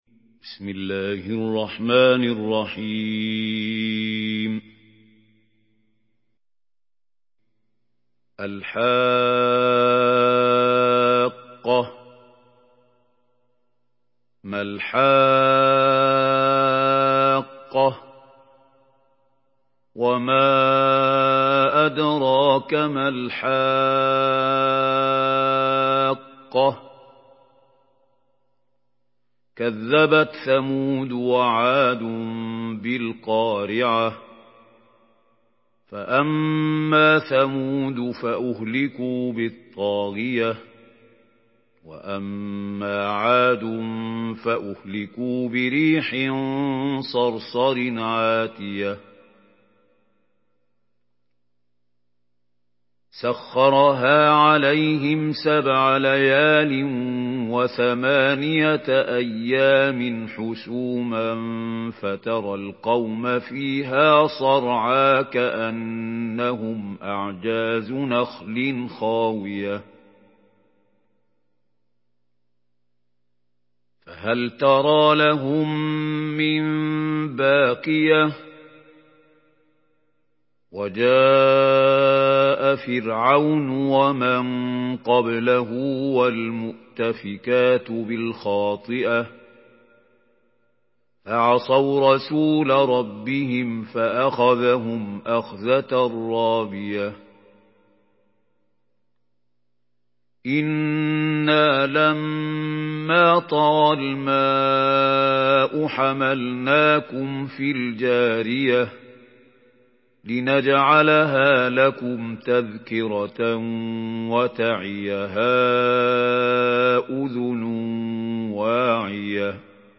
Surah Al-Haqqah MP3 by Mahmoud Khalil Al-Hussary in Hafs An Asim narration.
Murattal Hafs An Asim